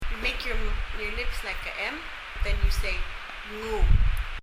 mnguu　　　　[mŋu:]　　　　　綴りも短いですし、一見簡単そうに思えますが、
例文の最初に mnguu が入っています。
発音